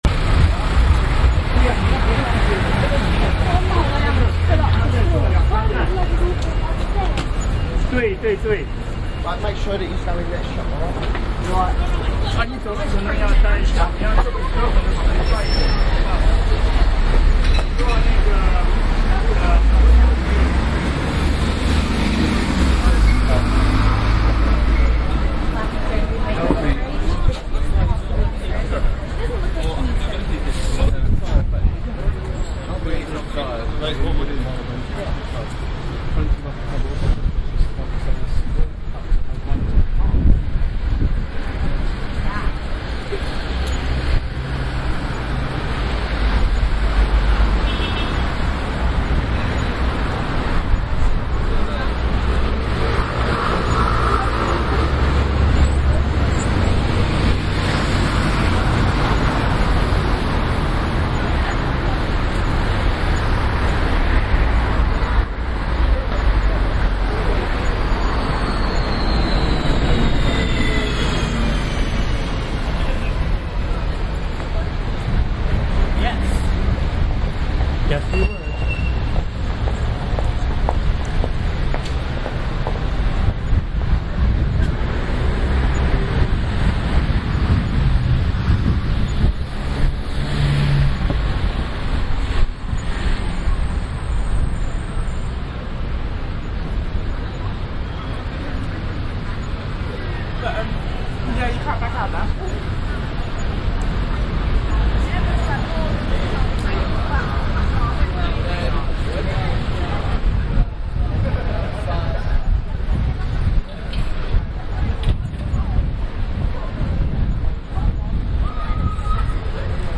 Mall Ambience
描述：Ambience taking while sitting in a food court in a shopping centre
标签： court Ambience Talking Voices OWI Food People Shopping
声道立体声